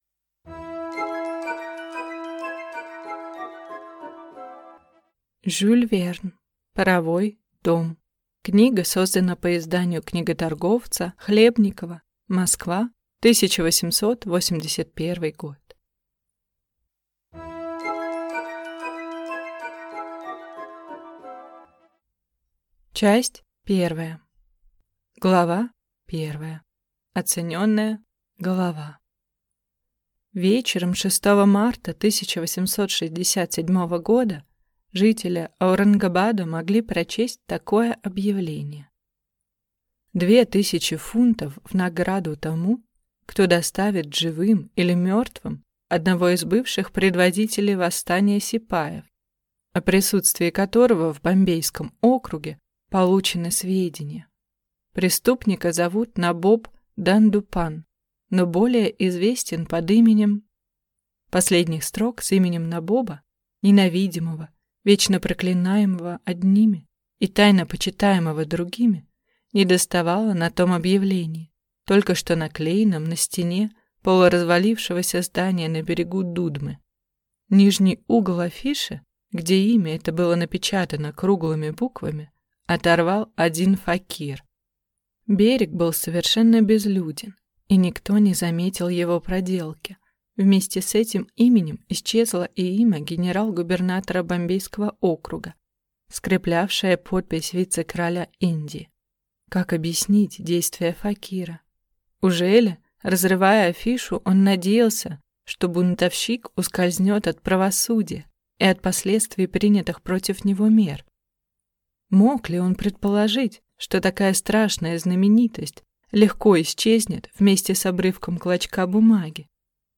Аудиокнига Паровой дом | Библиотека аудиокниг